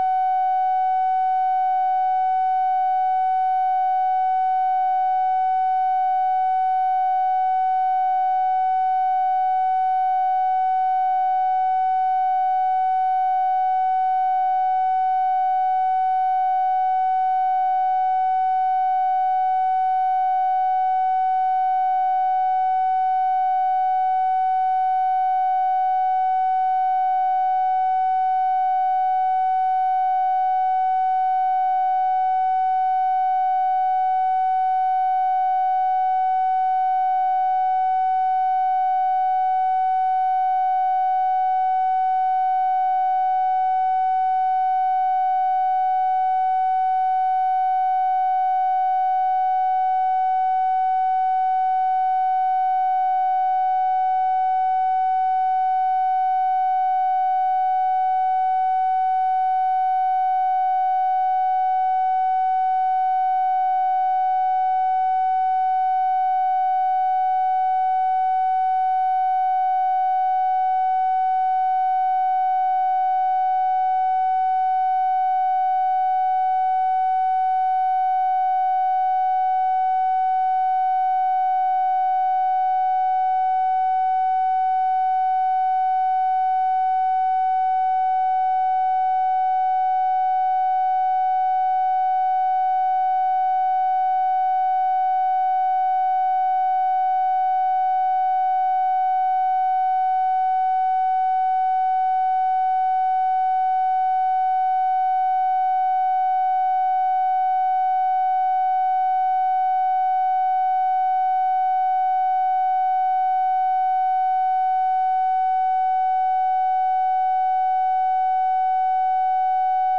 Conversation with JOHN MCCONE, MCGEORGE BUNDY and OFFICE CONVERSATION, February 9, 1964
Secret White House Tapes